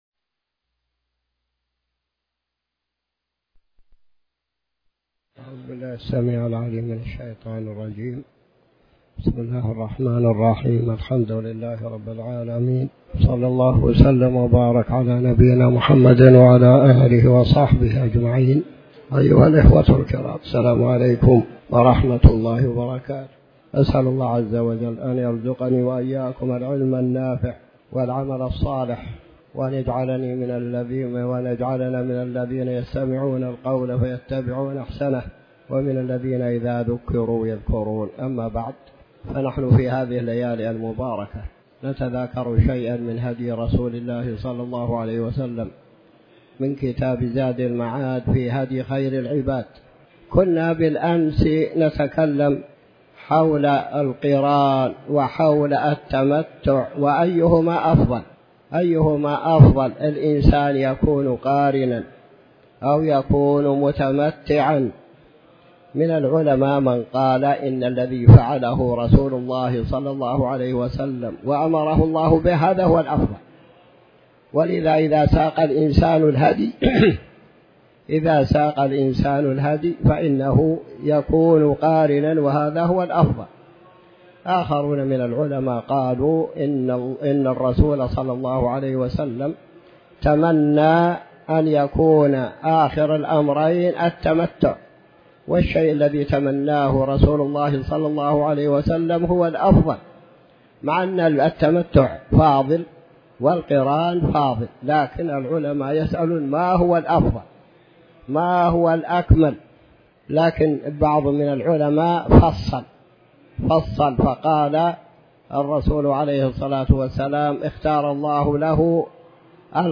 تاريخ النشر ٢٩ محرم ١٤٤٠ هـ المكان: المسجد الحرام الشيخ